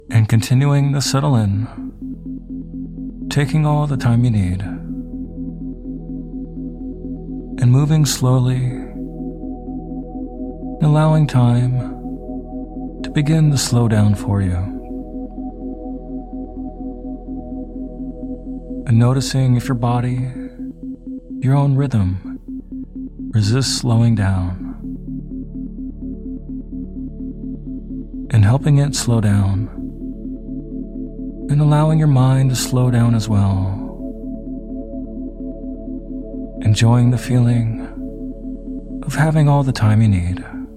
Guided Meditation For Having A Lucky Day With Isochronic Tones